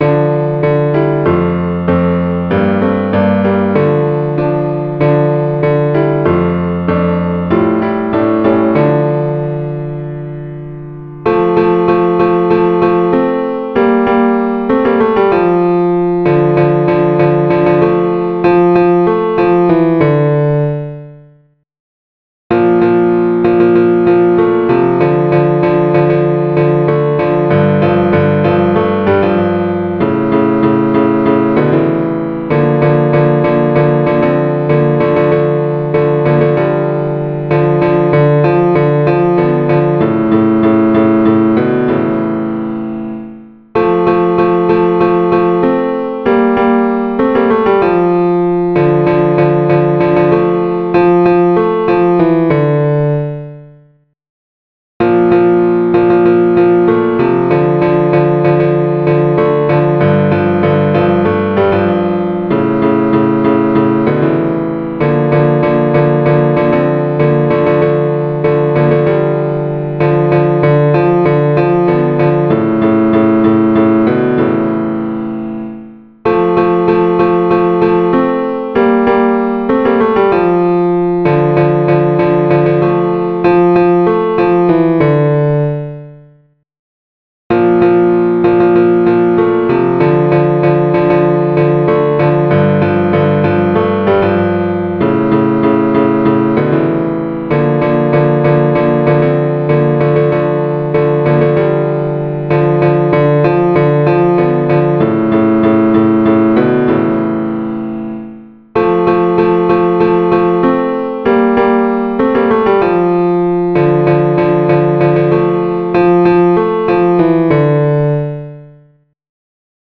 Fichiers audios pour l’entraînement
Tutti Ténor 1 Ténor 2 Baryton Basse